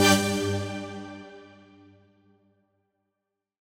FR_ZString[hit]-G.wav